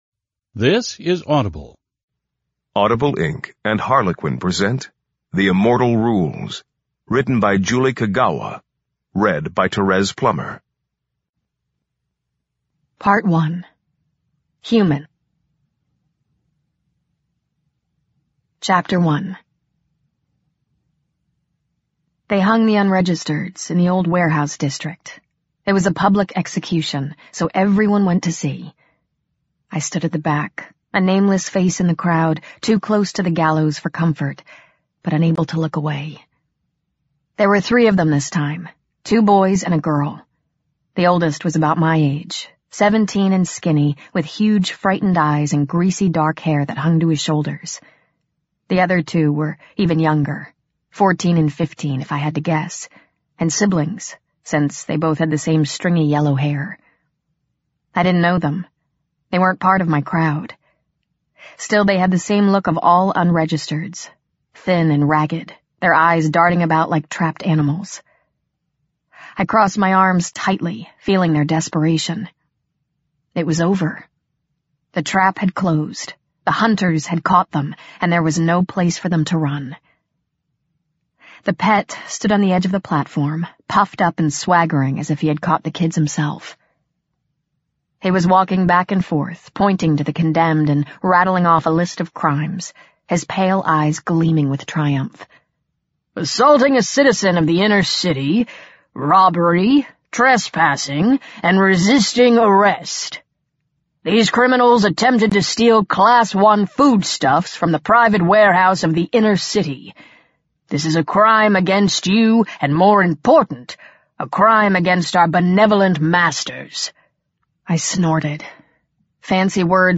Audiobook-The-Immortal-Rules-Sample.mp3